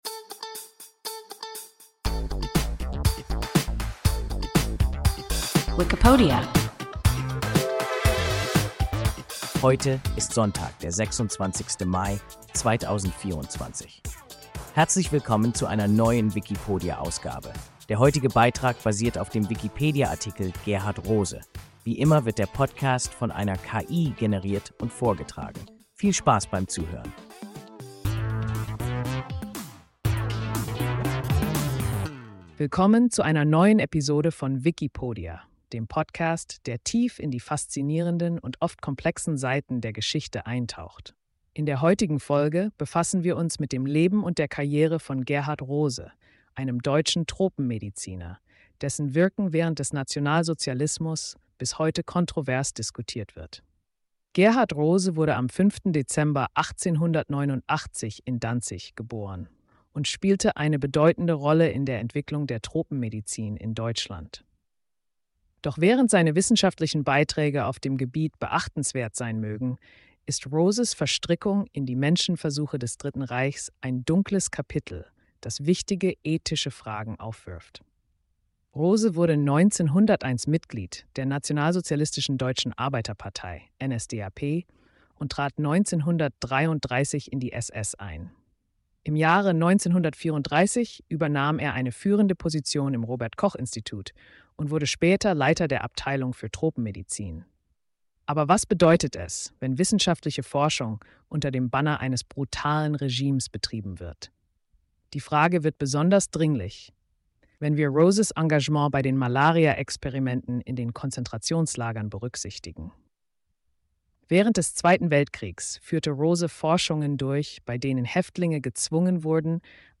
Gerhard Rose – WIKIPODIA – ein KI Podcast